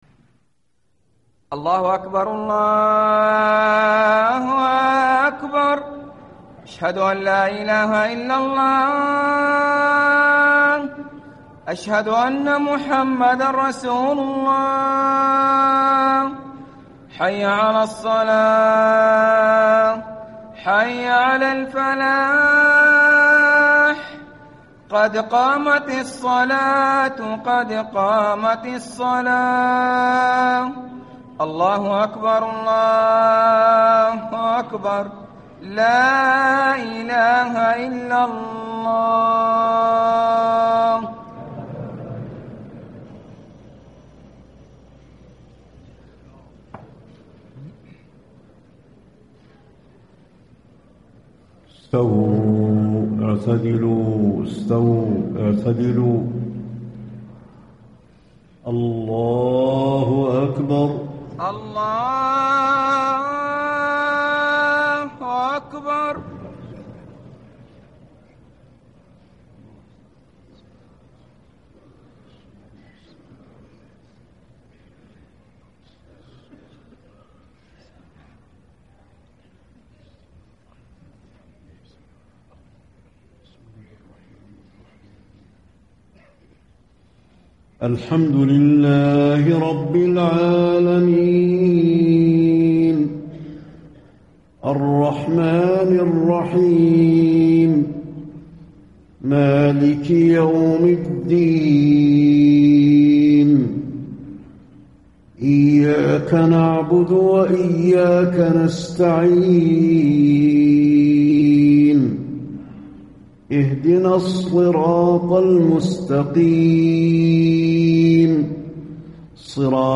صلاة العشاء 4 - 7 - 1435هـ سورتي البلد و الشمس > 1435 🕌 > الفروض - تلاوات الحرمين